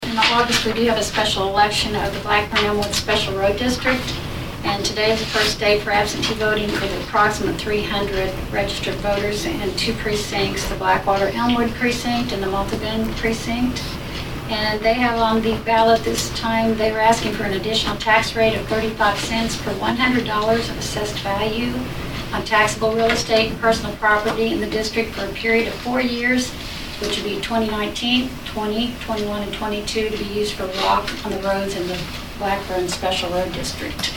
During the meeting of the Saline County Commission on Tuesday, June 25, Clerk Debbie Russell talked about the only issue that will be put before certain voters in the county.